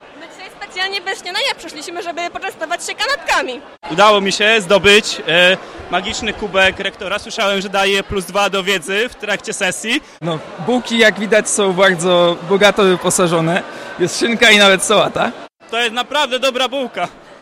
1005_studenci o śniadaniu.mp3